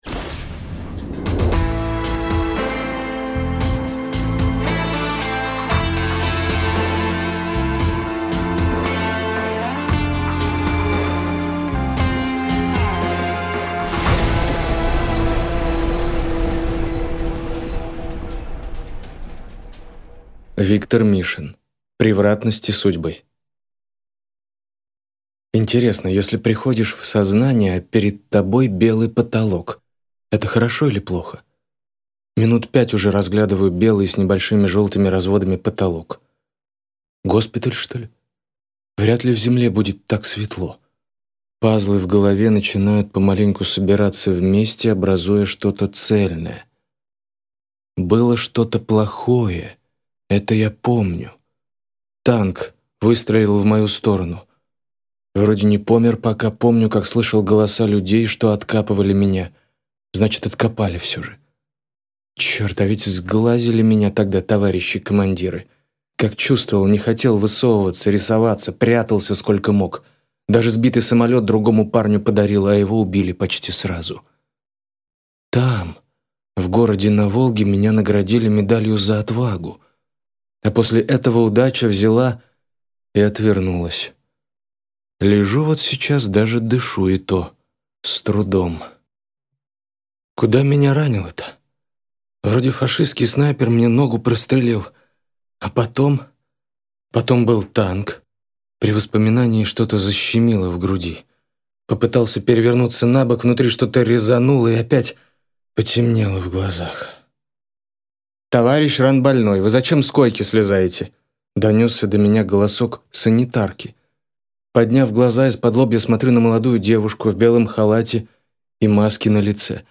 Аудиокнига Превратности судьбы | Библиотека аудиокниг